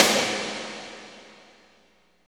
53.09 SNR.wav